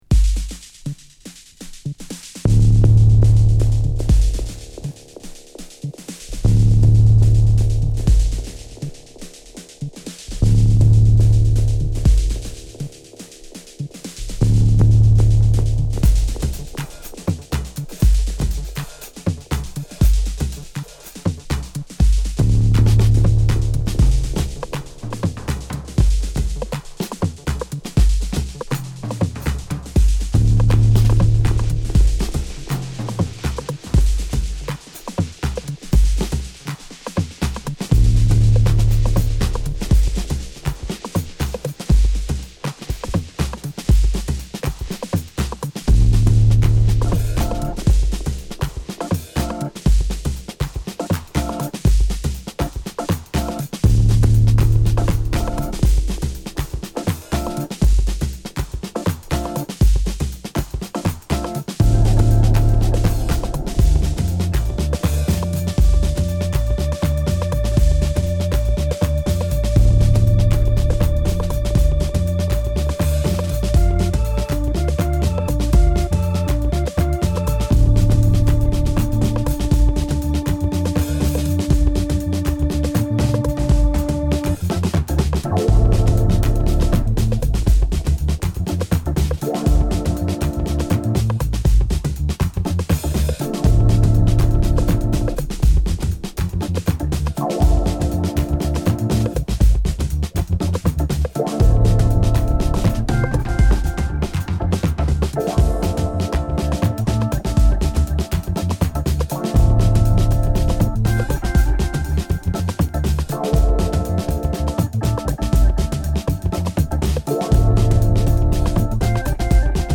変幻自在でとらえどころのない、サイケデリックな生ドラムとドラムマシンのセッションはオーディ エンスの度肝を抜いた。